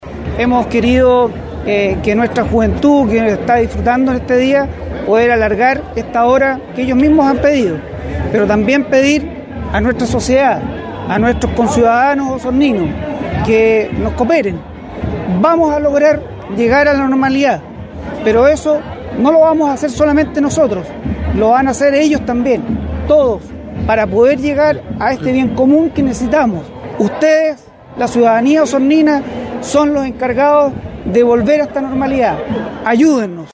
Cada vez menos disturbios en una manifestación pacífica que se convirtió en una fiesta en coordinación con militares - RadioSago
Plaza de Armas fue el punto de reunión al final de la manifestación, en donde el ambiente comenzó a cambiar, para convertirse en prácticamente una fiesta, luego que un reconocido empresario de la zona prendiera a la multitud con música.